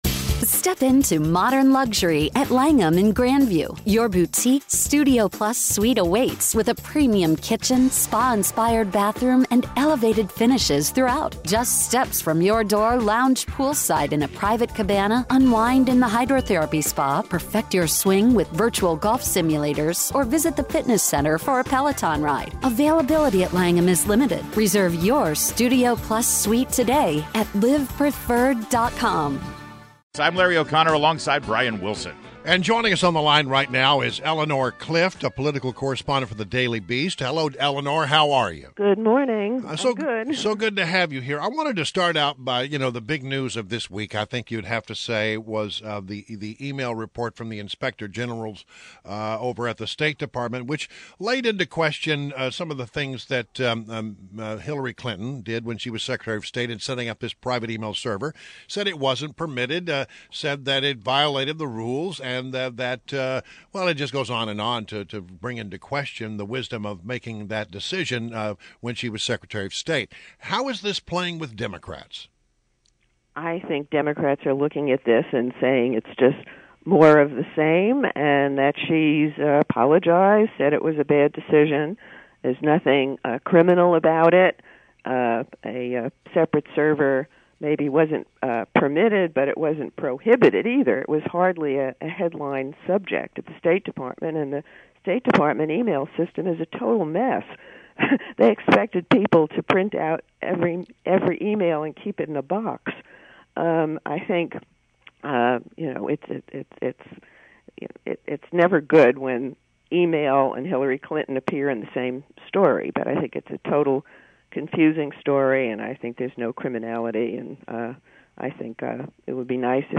WMAL Interview - ELEANOR CLIFT - 05.27.16
Daily Beast's political correspondent Eleanor Clift joined WMAL to analyze the Democratic side of the presidential election.